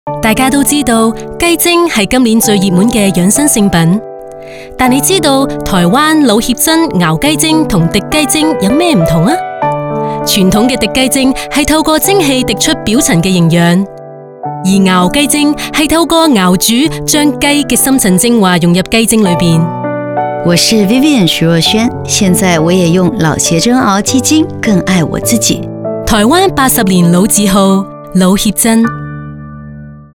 All our voice actors are premium seasoned professionals.
British Radio & TV Commercial Voice Overs Artists
Adult (30-50) | Yng Adult (18-29)